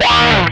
MANIC SLIDE1.wav